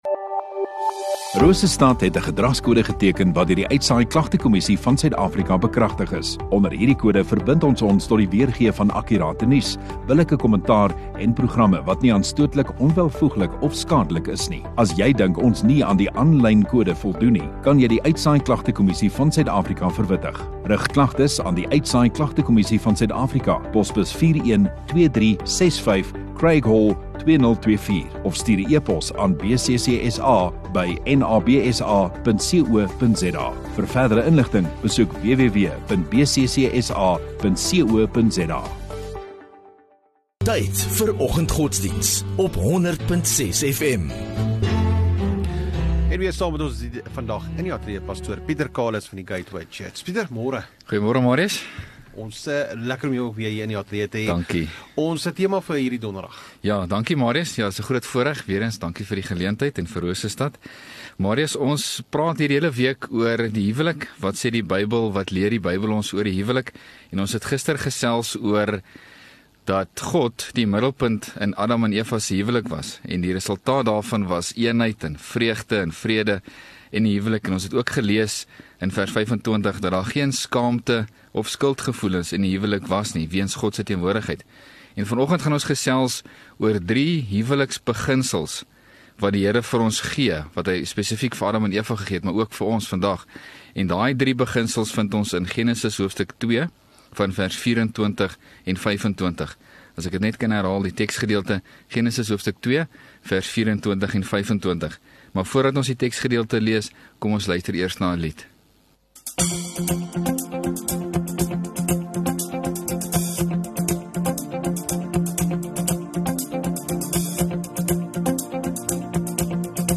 14 Nov Donderdag Oggenddiens